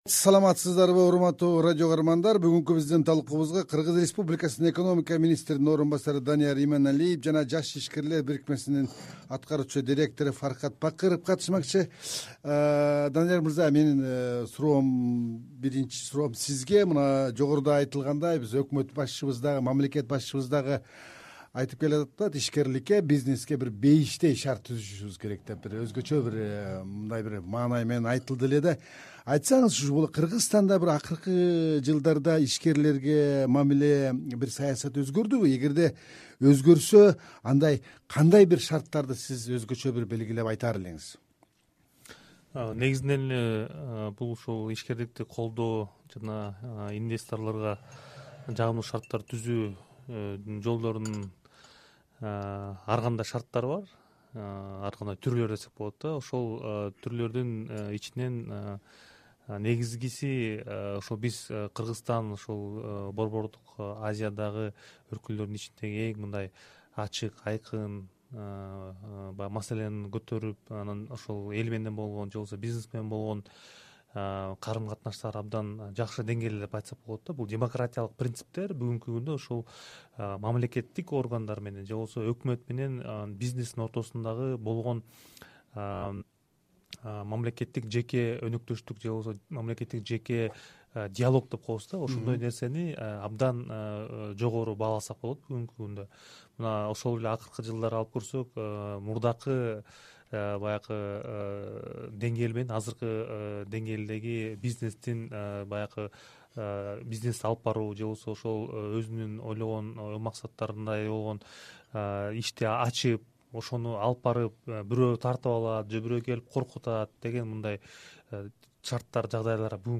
“Арай көз чарай” талкуусунда ушул суроолорго жооп издейбиз.